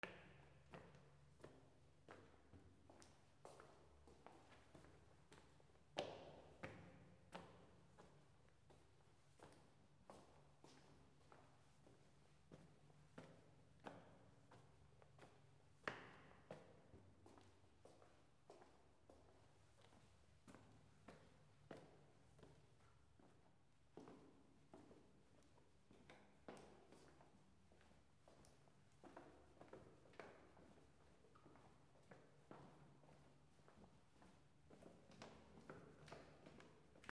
/ C｜環境音(人工) / C-25 ｜部屋、ルームトーン
非常階段上り下り
盛岡 D50